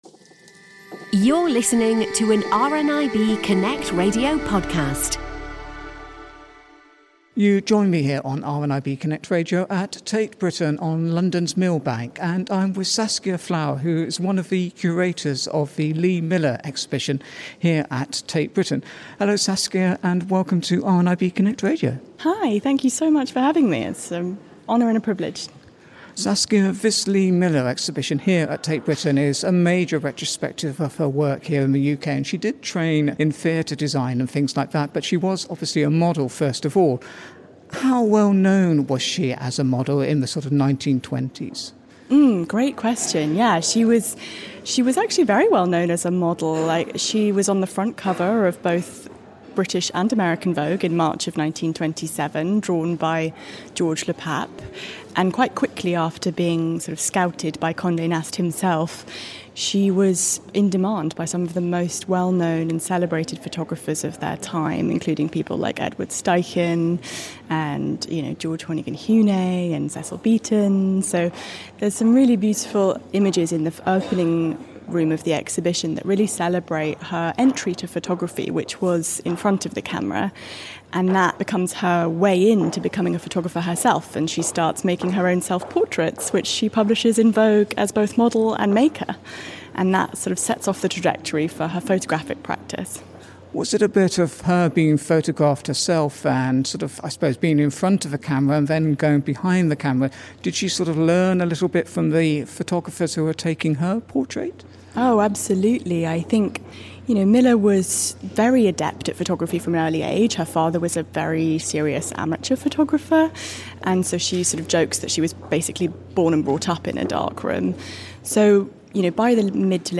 At the Press View of the Lee Miller exhibition